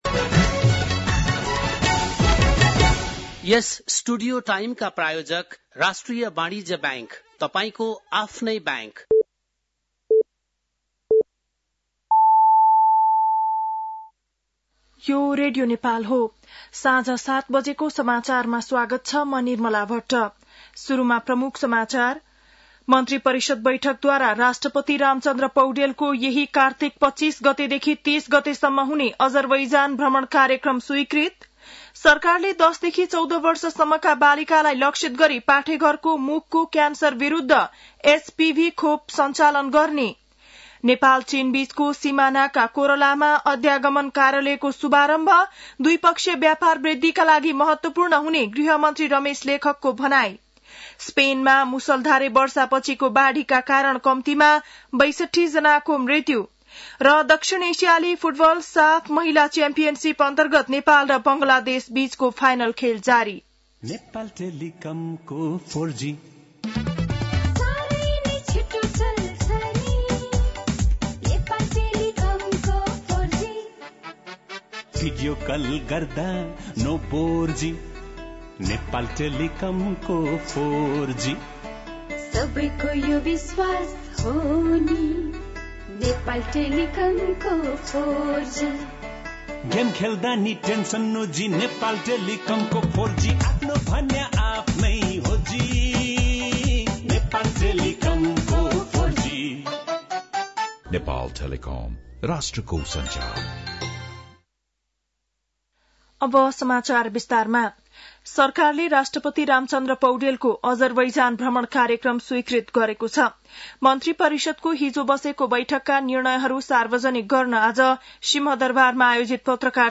बेलुकी ७ बजेको नेपाली समाचार : १५ कार्तिक , २०८१
7-pm-nepali-news-7-14.mp3